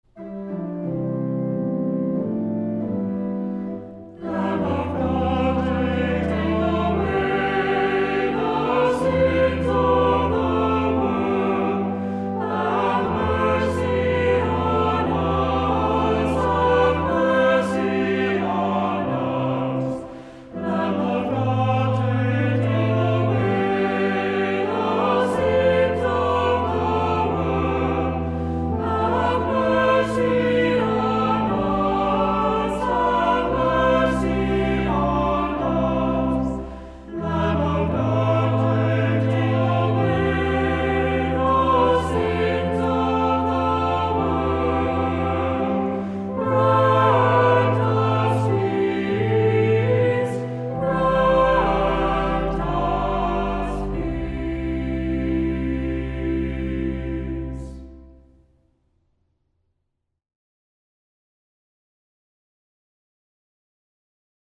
Voicing: SATB; Assembly